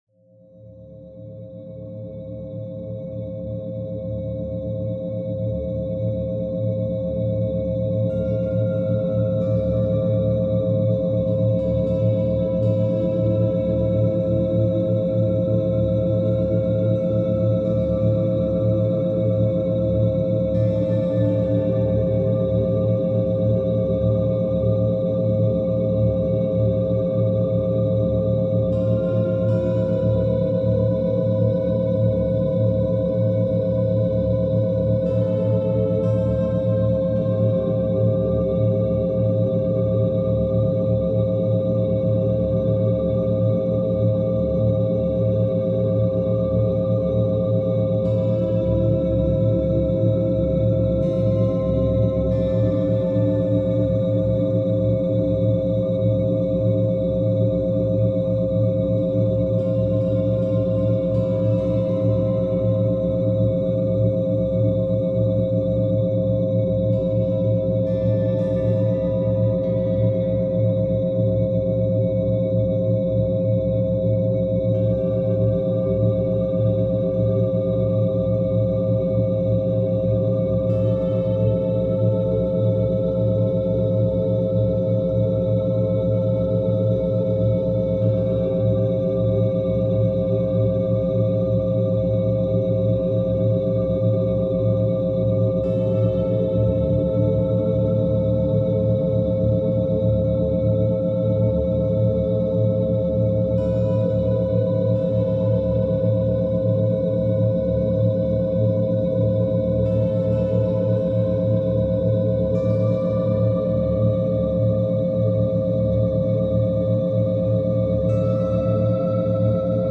雨が静かに窓を叩くように、雨の音が思考を包み込む。